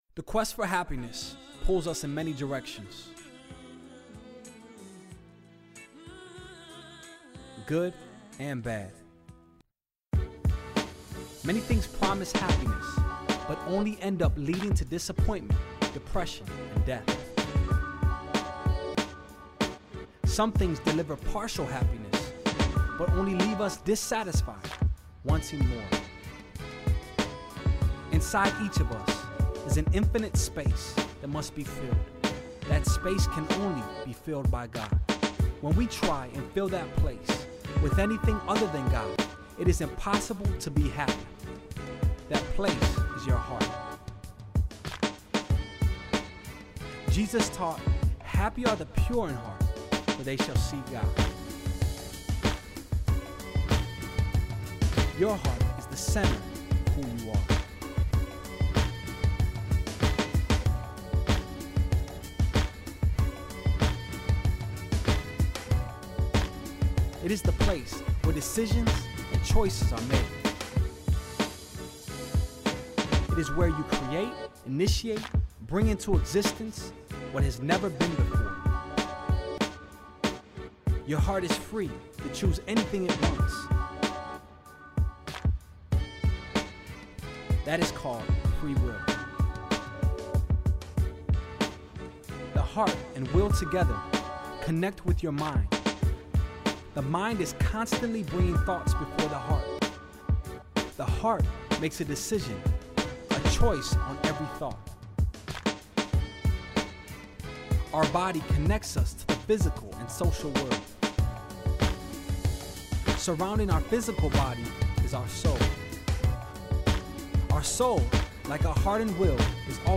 Hope Speaks ESL Audio Lesson 19